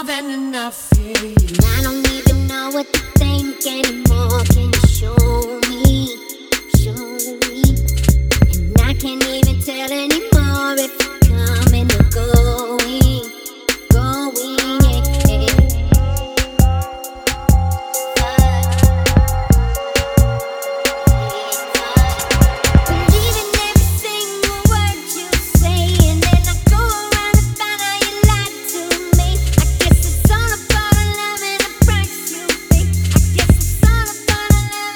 Жанр: R&b / Альтернатива / Соул